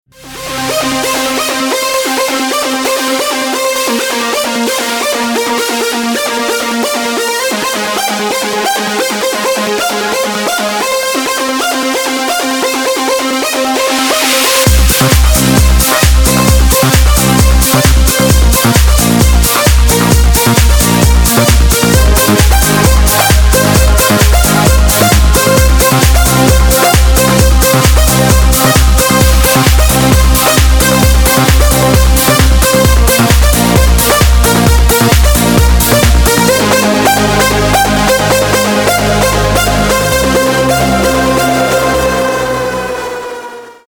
dance
Electronic
EDM
Club House
без слов
electro house
Melodic